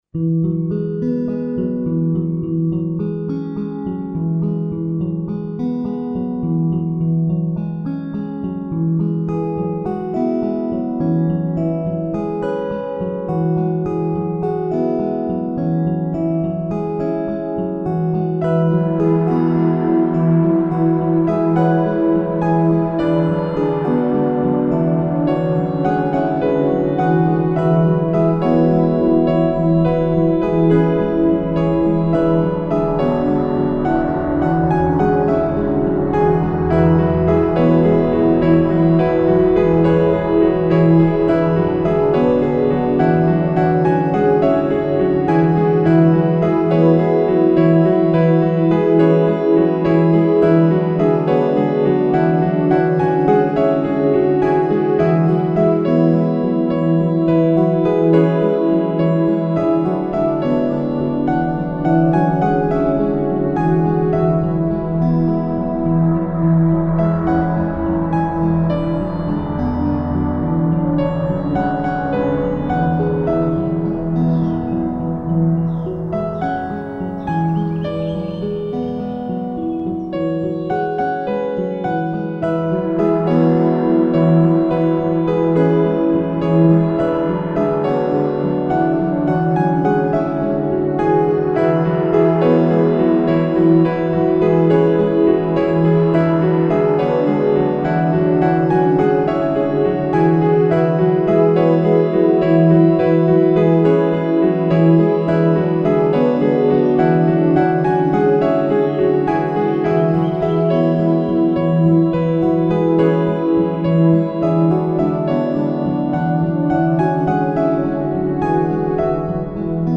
Piano Reprise